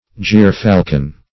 Gier-falcon \Gier"-fal`con\, n. [Cf. Gier-eagle, Gyrfalcon.]
gier-falcon.mp3